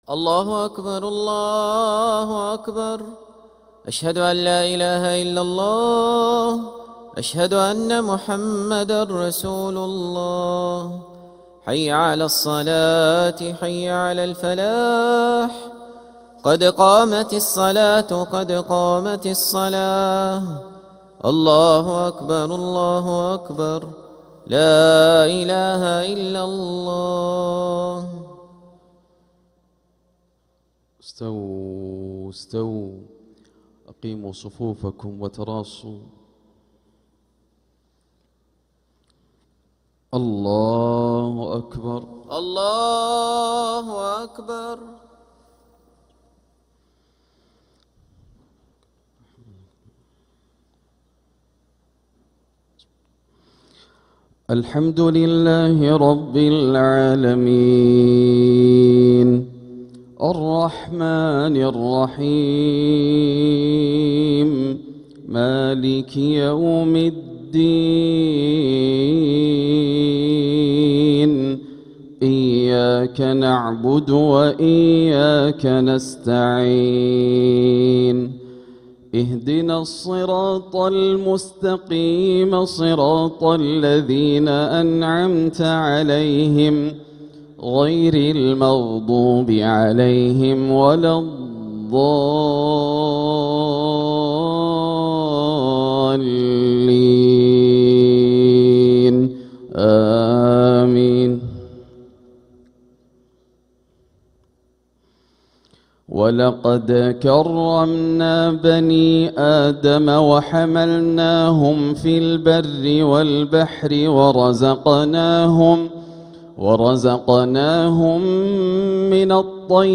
Makkah Fajr - 12th April 2026